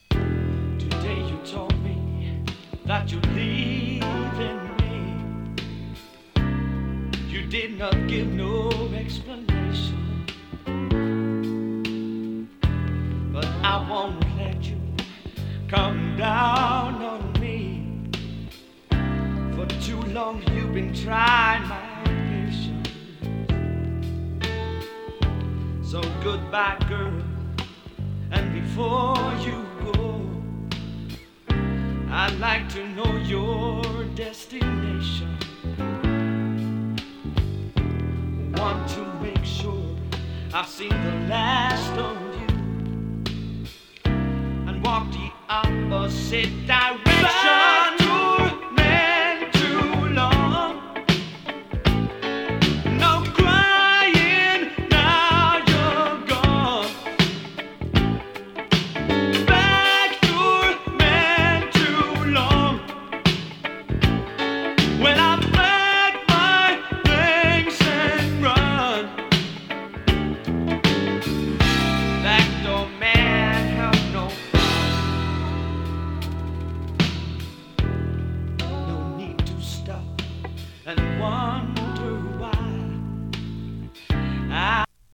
北欧産AOR